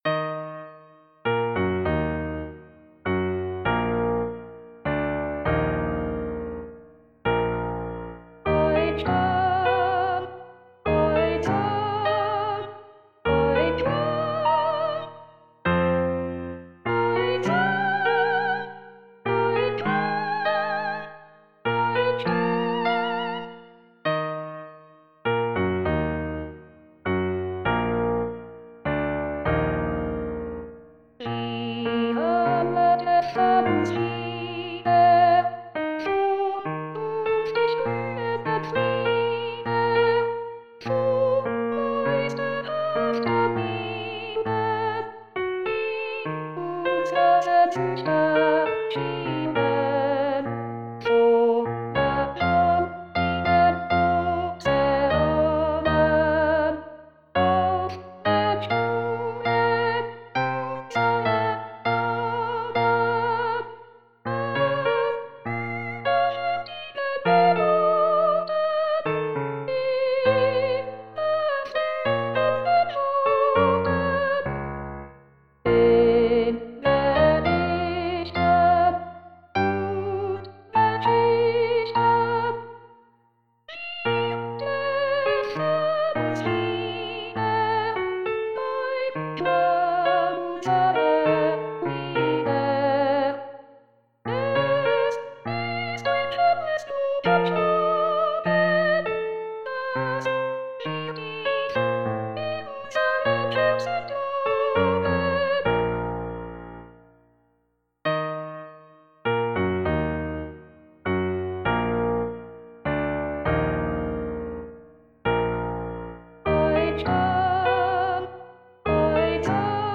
mp3-Aufnahme: Wiedergabe mit Gesang